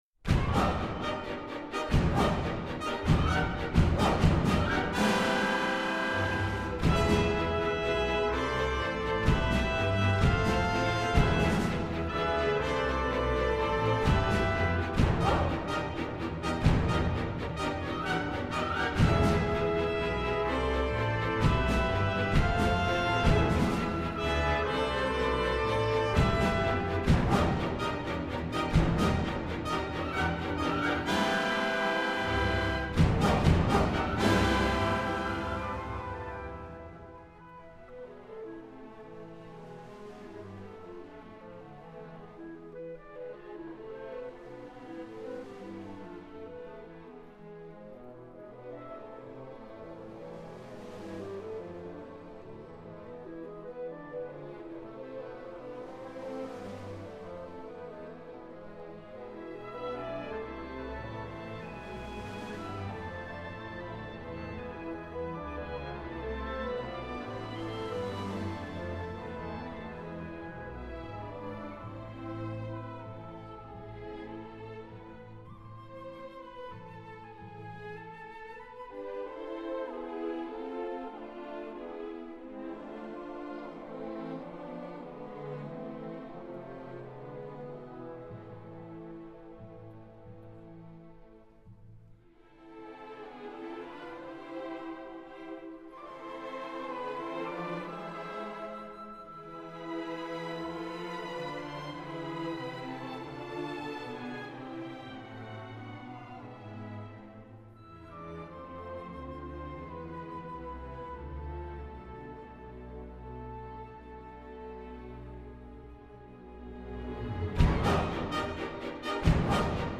Incontro
In conversazione con quest’ultimo scopriamo le riflessioni che hanno portato alla scelta dei brani di Anna Clyne, Camille Saint-Saëns ed Edward Elgar.